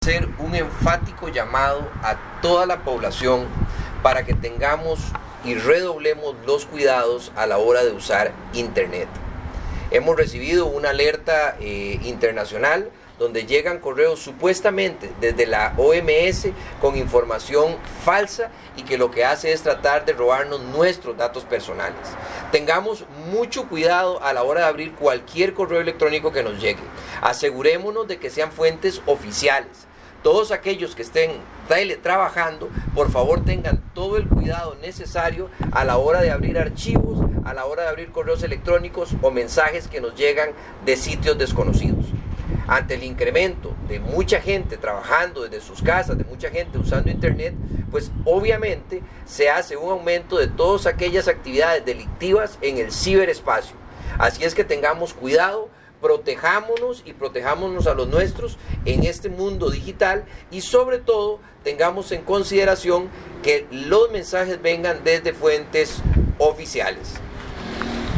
Declaraciones del ministro Luis Adrián Salazar sobre alerta técnica de la Organización Mundial de la Salud